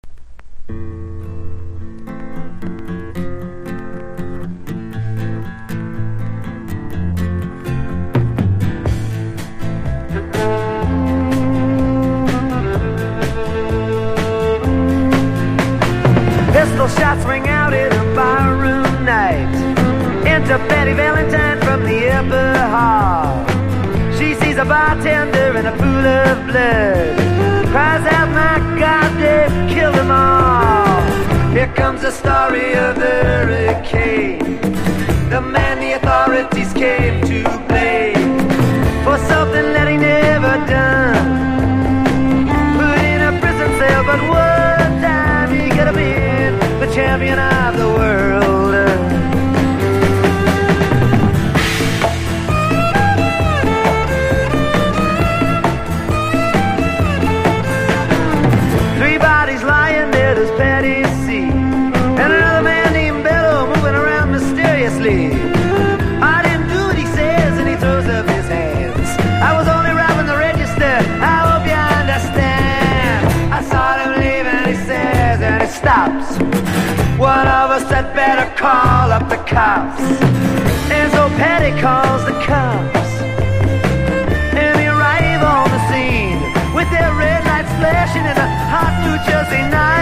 1. 70'S ROCK >
ジプシーバイオリン等を使用した無国籍風アレンジが特徴的な1枚。
SSW / FOLK